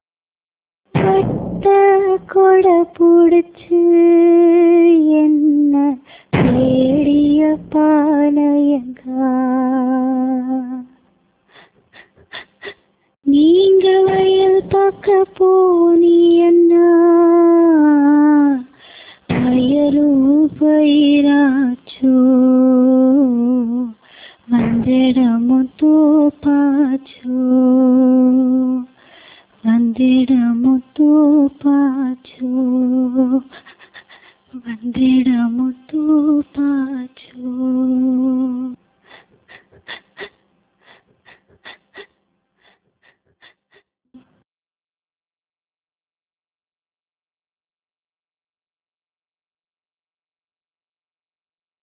(கணவனை இழந்த பெண் பாடுவது)